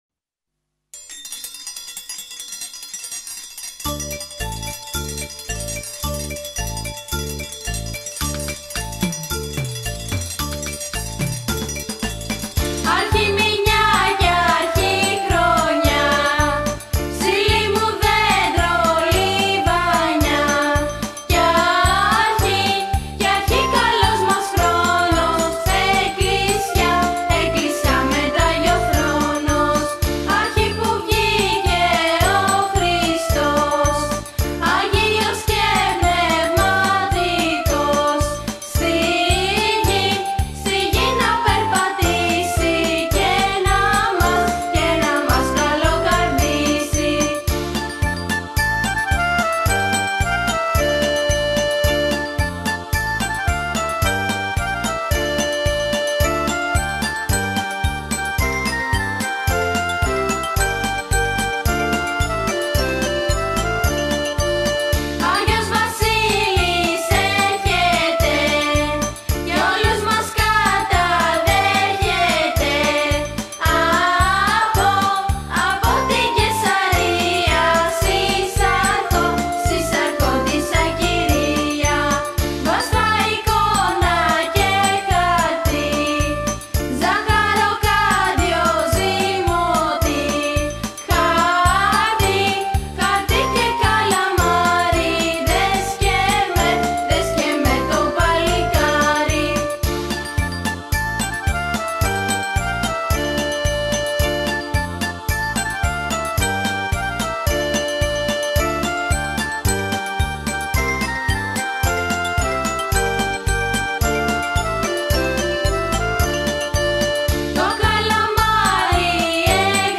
Κάλαντα-Πρωτοχρονιάς-2015-Αρχιμηνιά-κι-αρχιχρονιά-.mp3